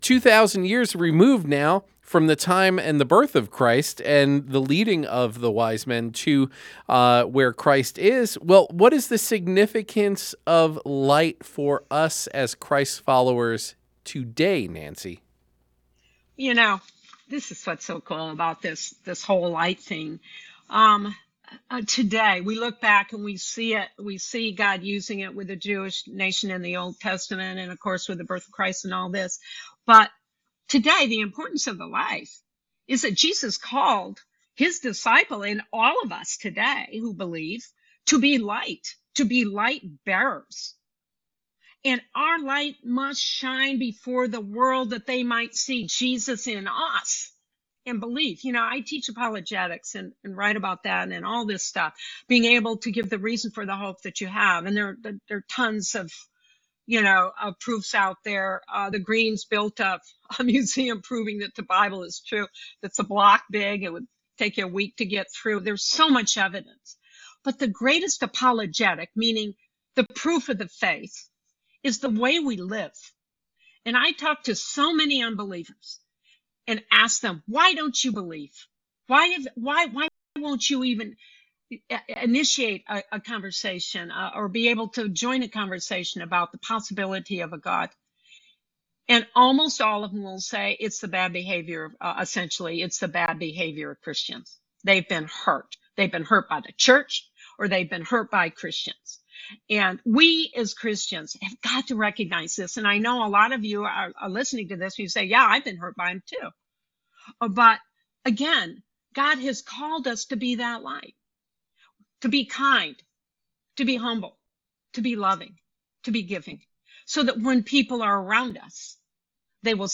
recorded live on Moody radio